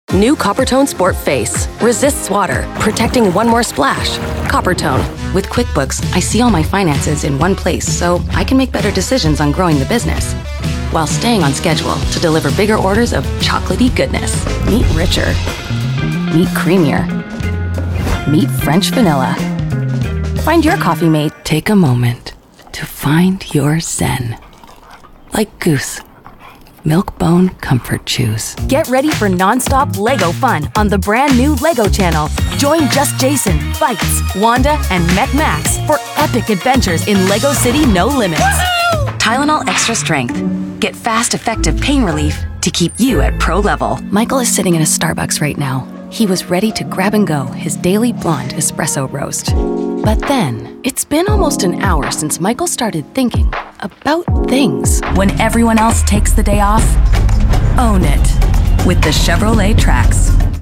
Commercial
English - Midwestern U.S. English
An authentic, conversational, cool voice that's not announcer-y. Unless you want that.
Warm, Relatable, Millennial, Mom, Authentic, Cool, Texture, Rasp, Dynamic, Actor, Comedic, Comedian, Improv, Funny, Effortless, Authoritative, Bold, Automotive, Luxury, Real, Canadian